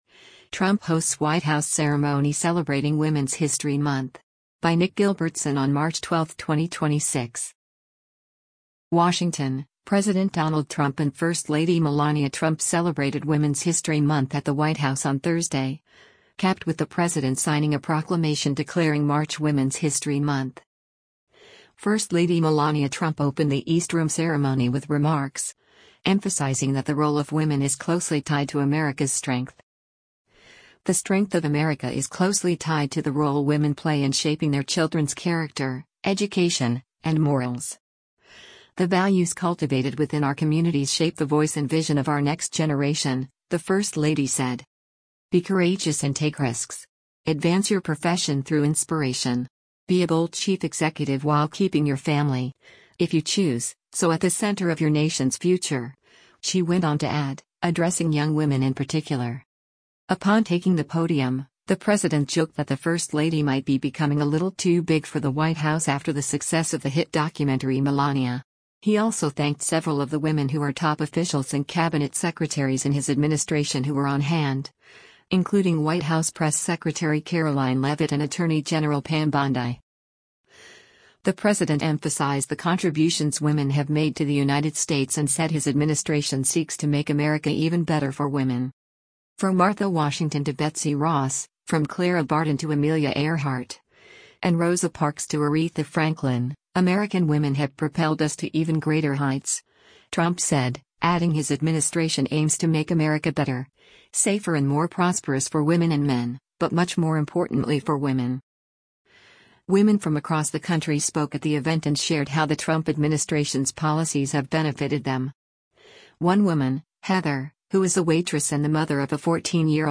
First lady Melania Trump opened the East Room ceremony with remarks, emphasizing that the role of women “is closely tied” to America’s strength.